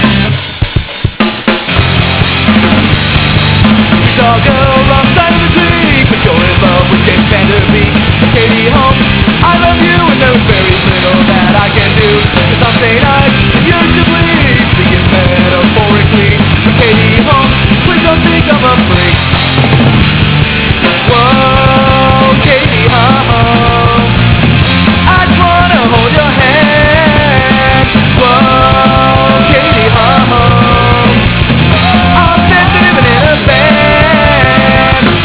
A sample of a song